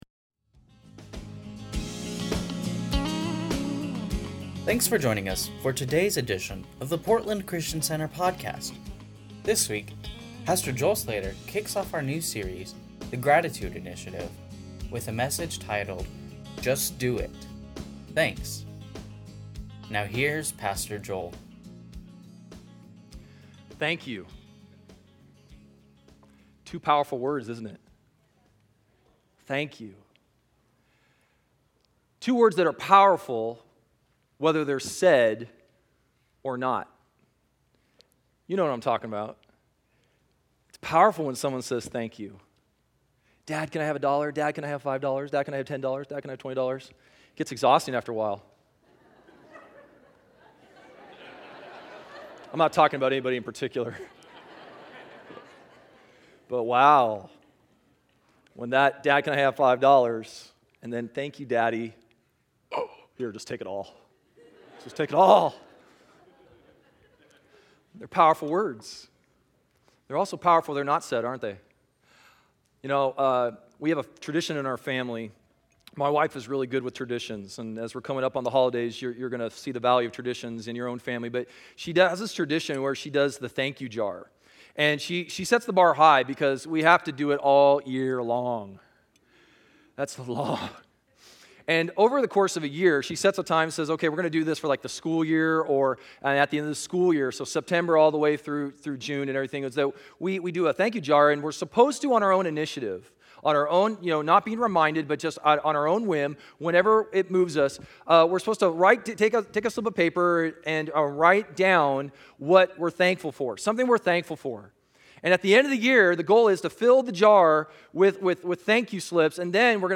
Sunday Messages from Portland Christian Center Just Do It.